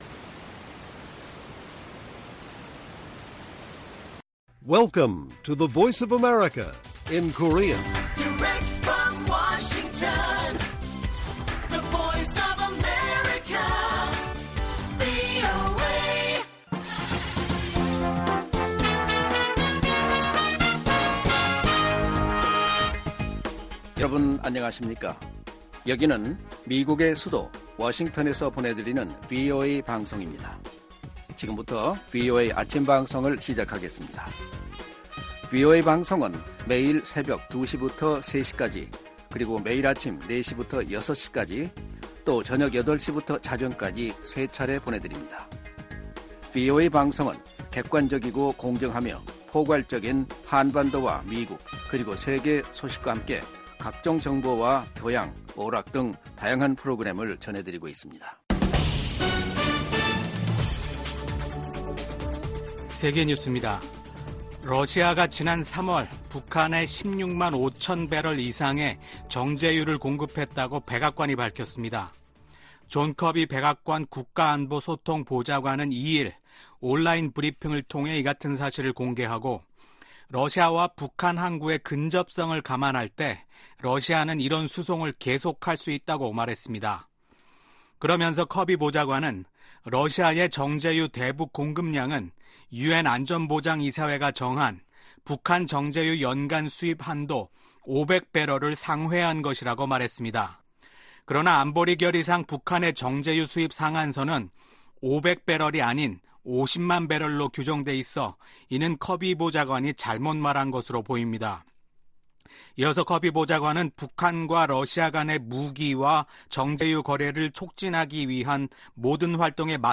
세계 뉴스와 함께 미국의 모든 것을 소개하는 '생방송 여기는 워싱턴입니다', 2024년 5월 3일 아침 방송입니다. '지구촌 오늘'에서는 미국 정부가 러시아 군수산업을 지원한 기관과 개인을 무더기로 제재한 소식 전해드리고, '아메리카 나우'에서는 미국 연방준비제도가 기준금리를 동결한 이야기 살펴보겠습니다.